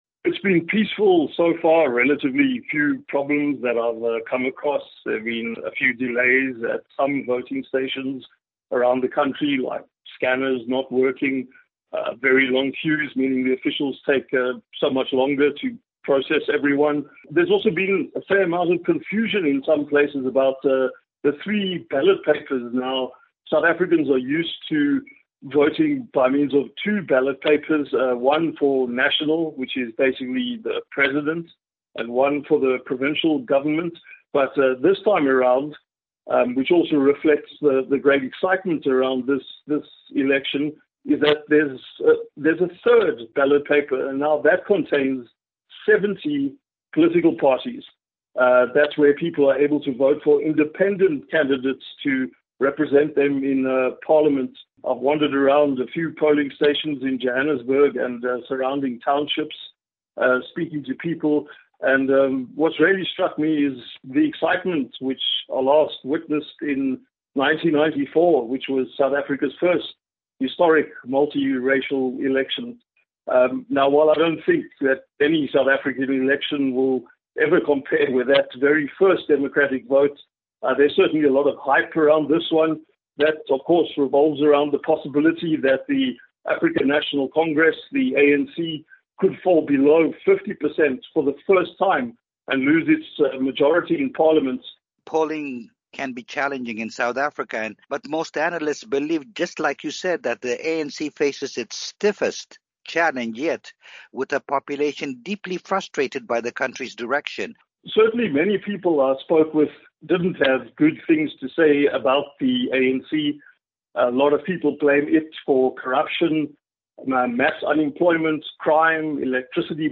Our reporter